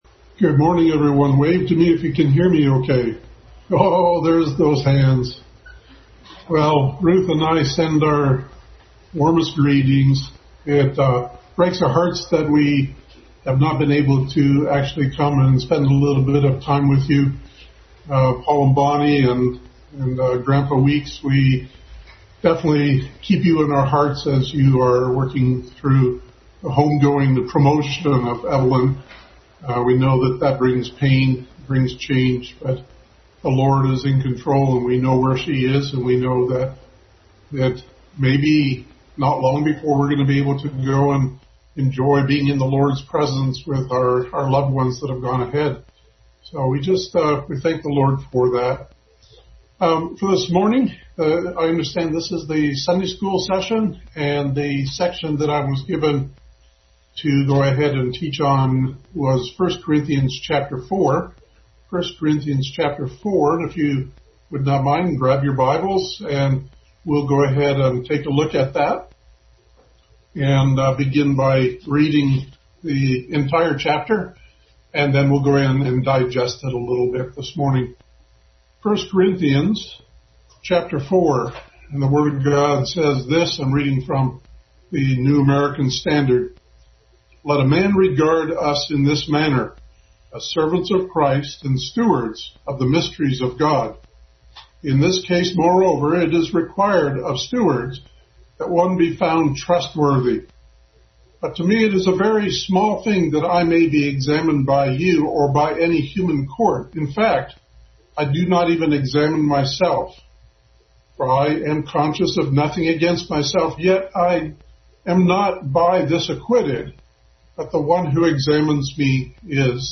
Bible Text: 1 Corinthians 4:1-21; 16:8; 3:5-11 | Adult Sunday School Class continued study in 1 Corinthians.
Service Type: Sunday School